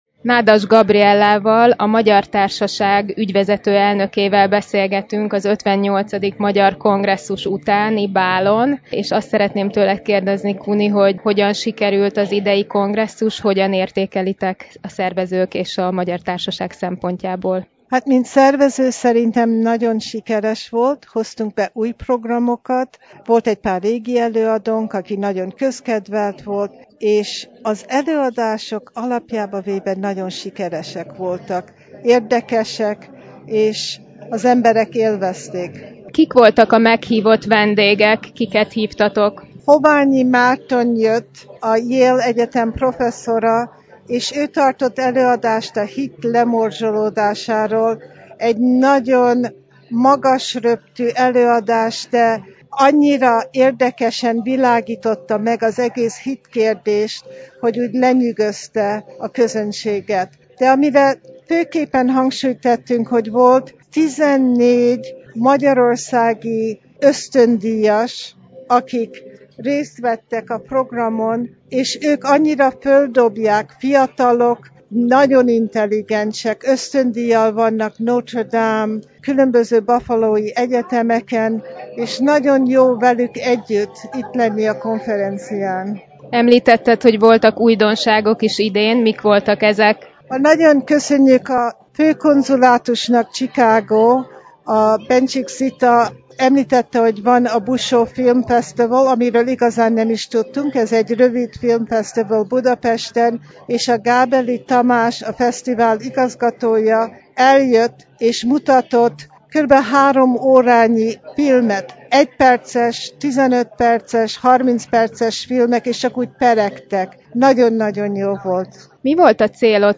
A Harmonia zenekar aztán hajnalig húzta a muzsikát, s miközben zajlott a mulatság, mi mikrofonvégre kaptuk